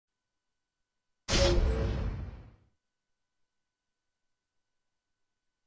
longclick.wav